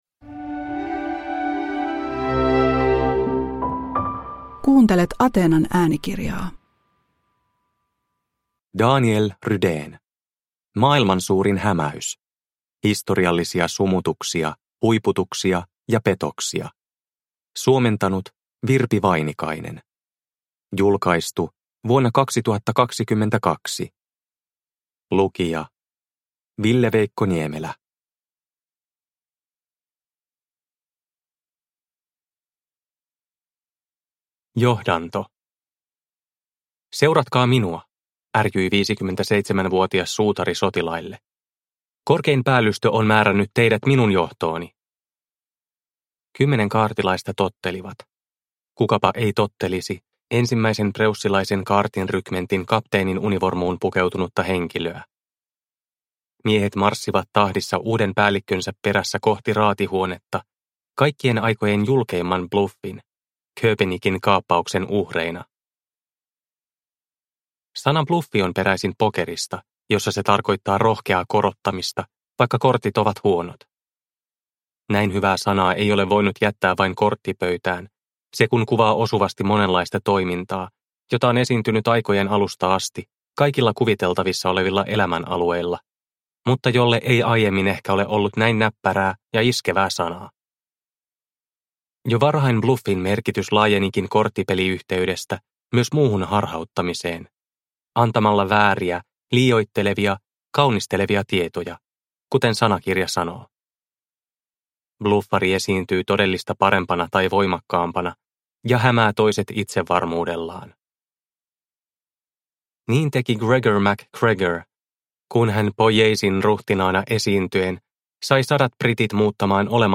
Maailman suurin hämäys – Ljudbok – Laddas ner